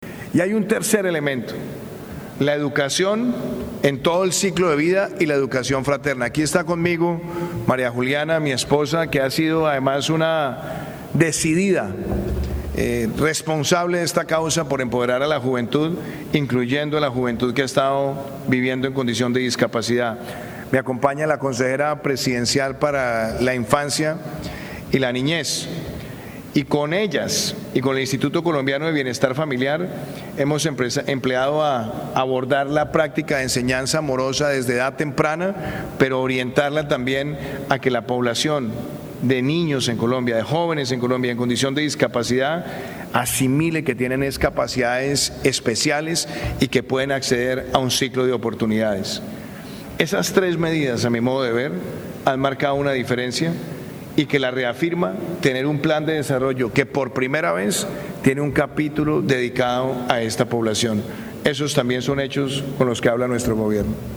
Durante este encuentro organizado por Nueva Economía Fórum en el Casino Gran Círculo de Madrid, Duque agradeció el respaldo y el trabajo de la ONCE, cuyo presidente Miguel Carballeda asistió en primera fila al encuentro, después de recibir además una copia del cupón enmarcado que la ONCE dedica a este país en su sorteo del próximo 6 de diciembre, con el lema ‘Colombia diversa y vital’.